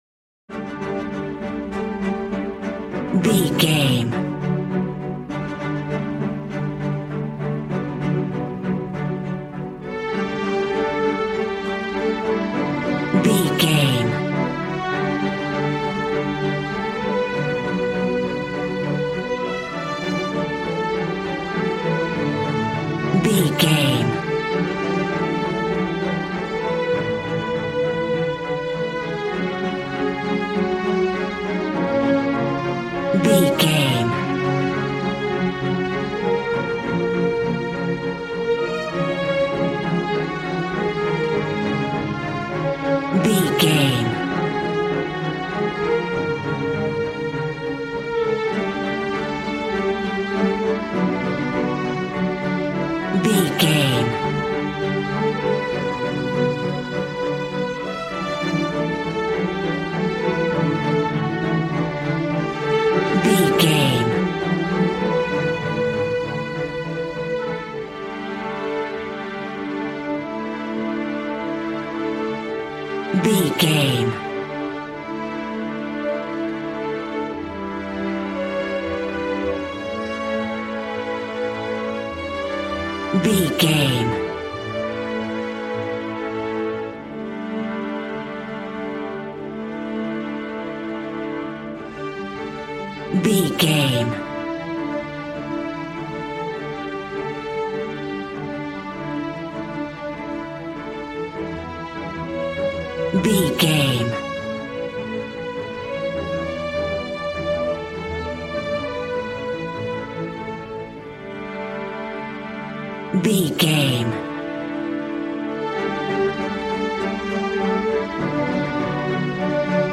Aeolian/Minor
regal
strings
brass